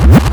REVERSBRK2-L.wav